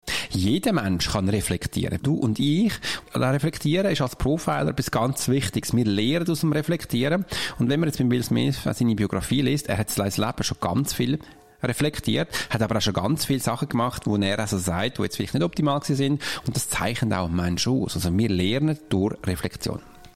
Dieses Interview gibt es auch auf Hochdeutsch!